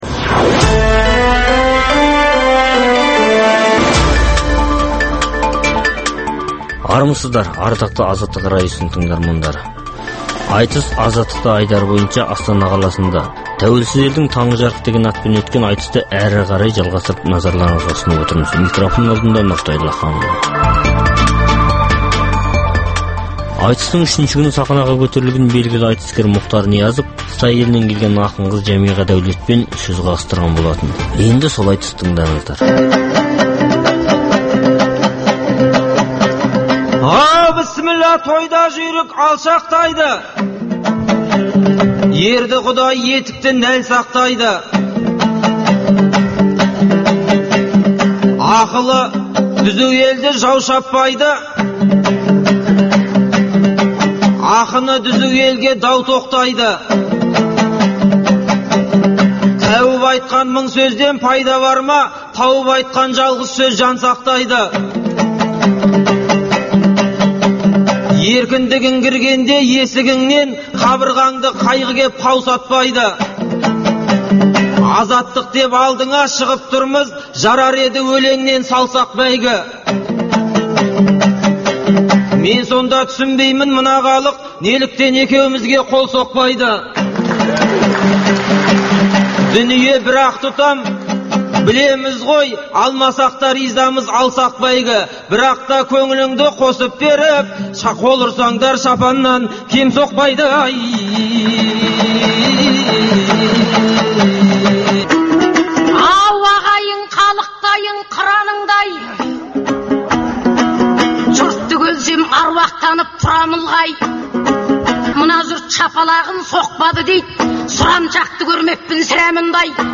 Айтыс - Азаттықта
аламан айтыстың